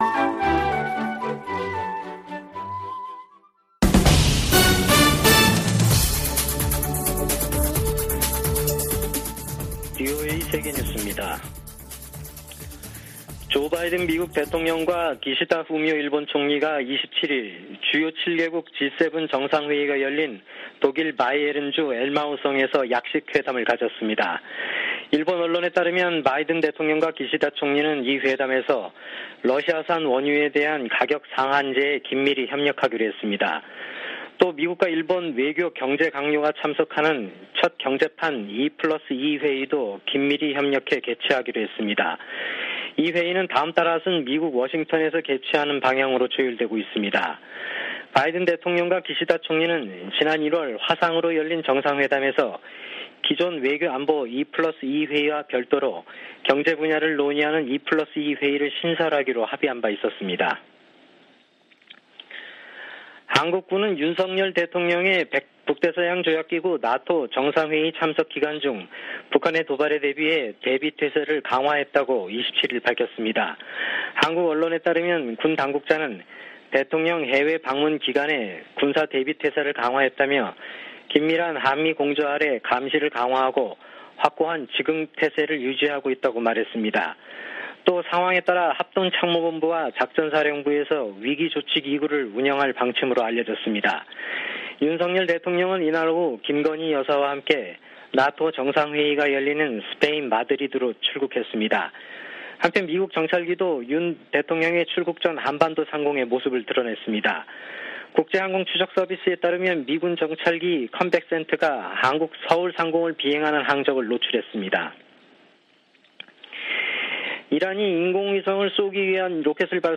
VOA 한국어 아침 뉴스 프로그램 '워싱턴 뉴스 광장' 2022년 6월 28일 방송입니다. 권영세 한국 통일부 장관은 북한이 7차 핵실험을 할 경우 엄청난 비판에 직면할 것이라고 경고했습니다. 미국 의회 산하 위원회가 한국의 난민정책에 관한 청문회에서 문재인 정부에 의한 탈북 어민 강제북송을 비판했습니다. 알래스카 미군 기지가 북한의 미사일 위협을 24시간 감시하고 있다고 강조했습니다.